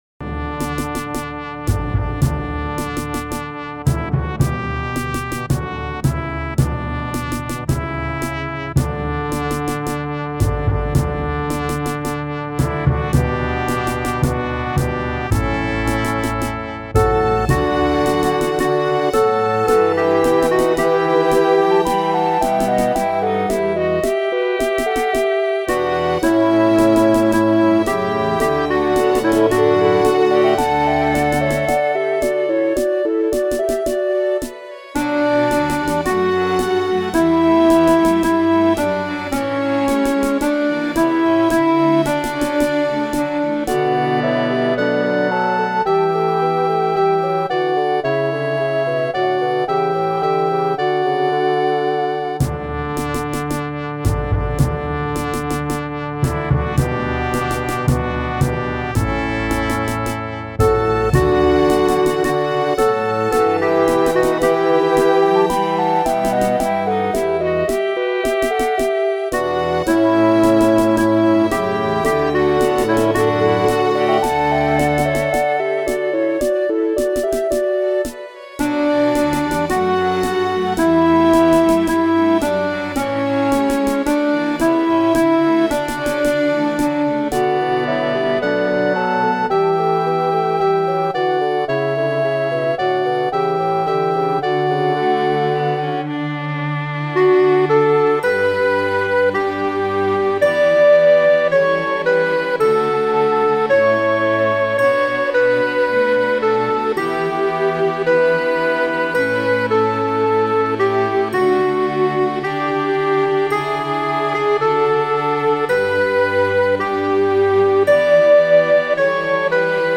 with a wonderful 12 part, full orchestral MIDI arrangement.